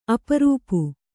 ♪ aparūpu